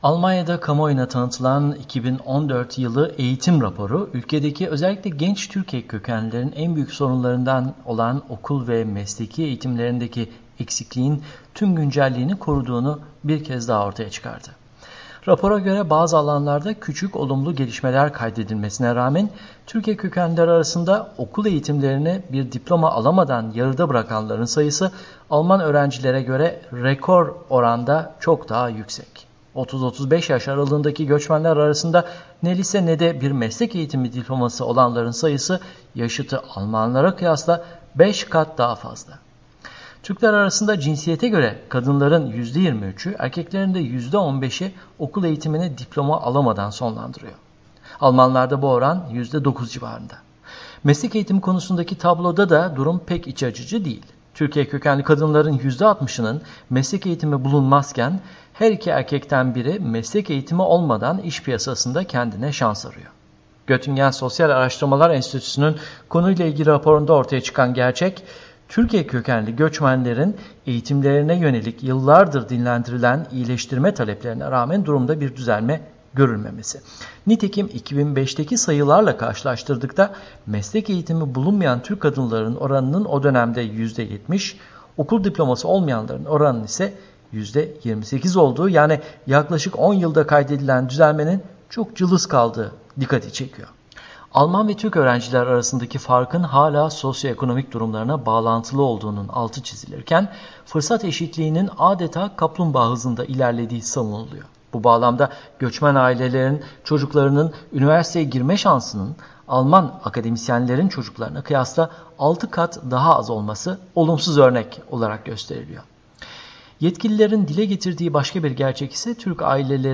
haberi